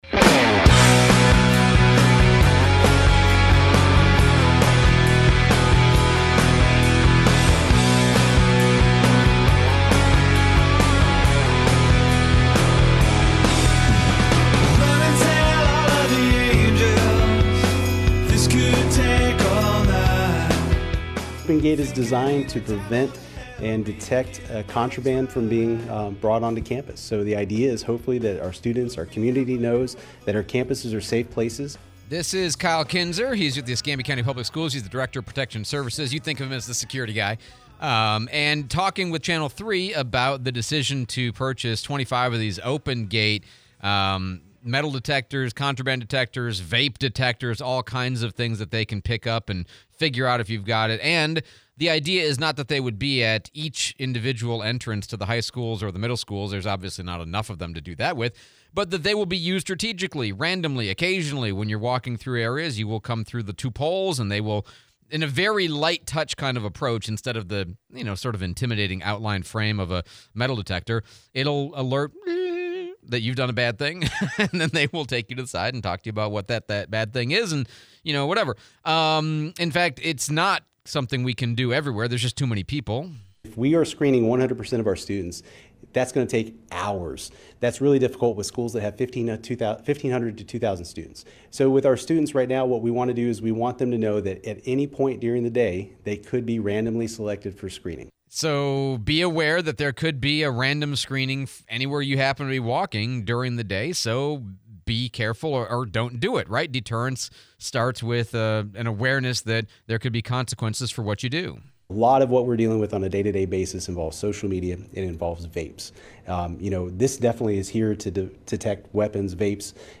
School security and random screening for firearms and other prohibited items / Interview with Mayor D.C. Reeves